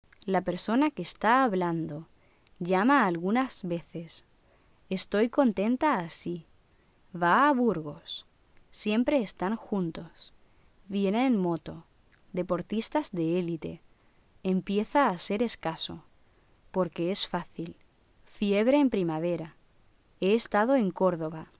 Sinalefas y encadenamientos
Sinalefas entre vocales idénticas, como en “va a Burgos” (vocal + vocal)